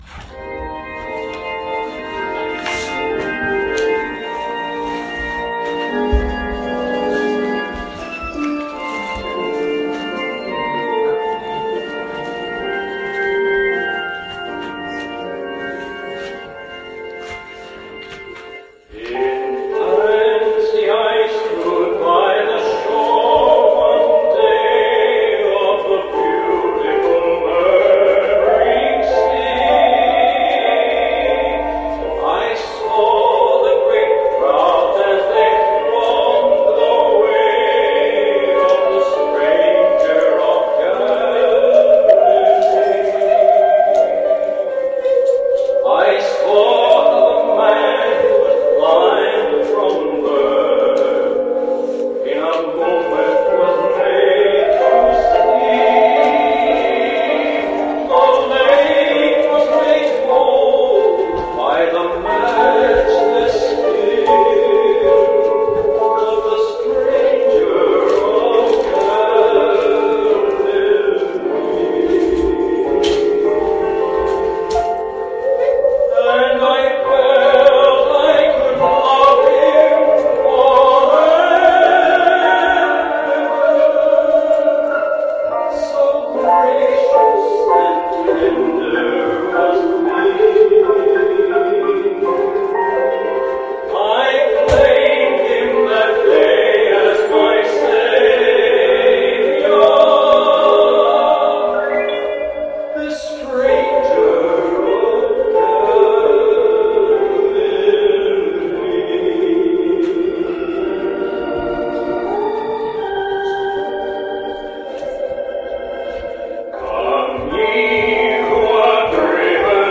Special performances
Played on the Saw